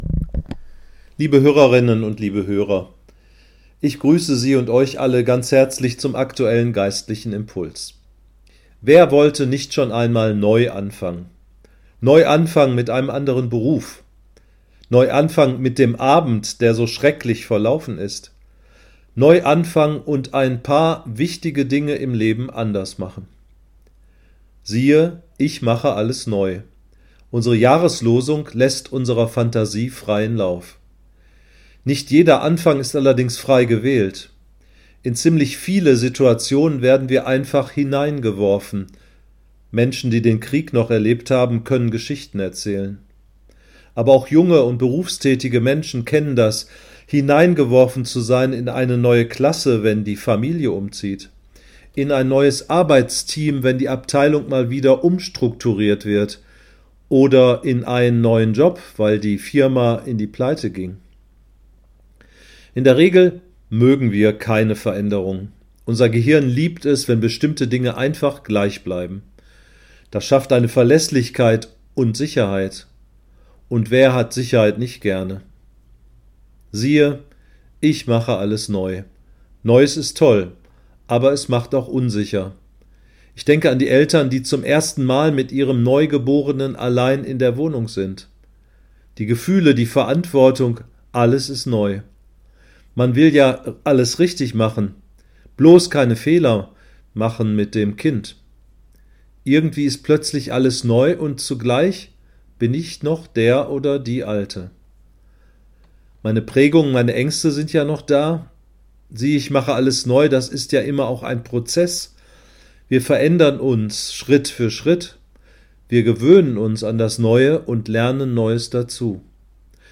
Geistlicher Impuls für den 11.01.2026: Siehe, ich mache alles neu.